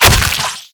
flesh-05.ogg